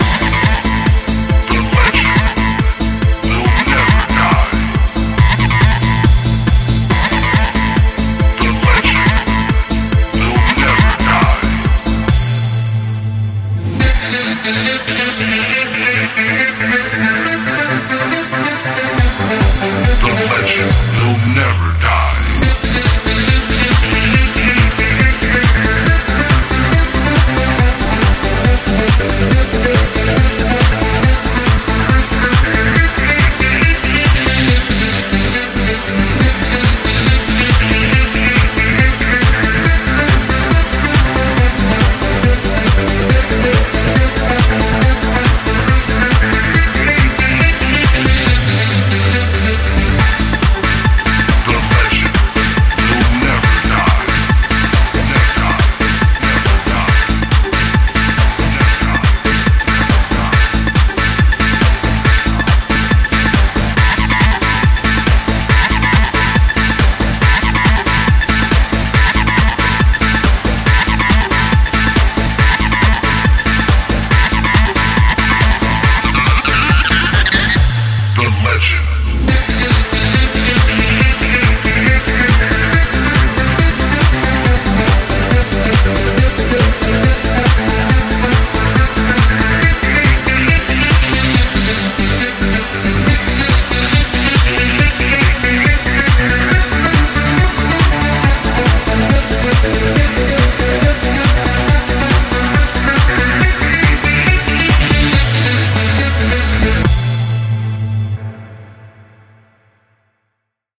BPM139
Audio QualityMusic Cut
The dodgy fadeout strikes back here as well.